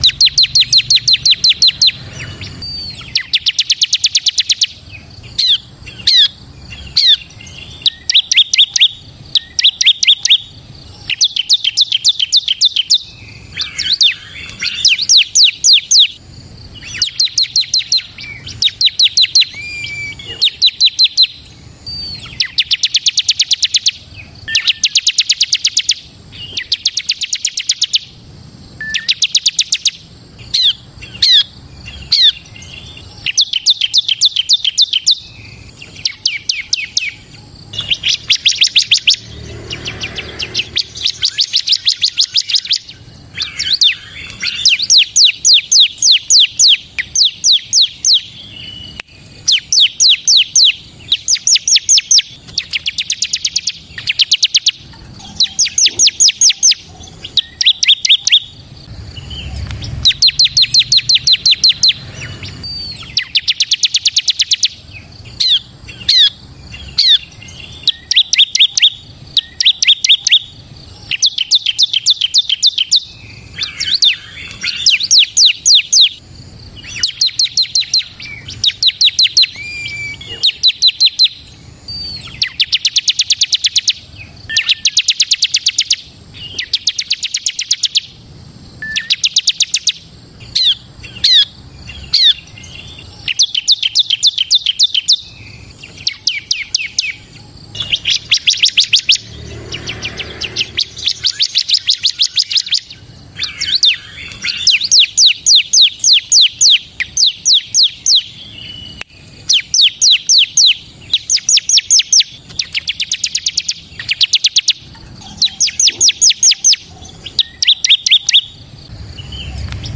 Suara Ciblek Kristal Ngebren Panjang
Kategori: Suara burung
Ciblek kristal gacor full ngebren dengan 6 variasi suara, cocok untuk melatih ciblek bren kesayangan Anda agar menjadi juara.
suara-ciblek-kristal-ngebren-panjang-id-www_tiengdong_com.mp3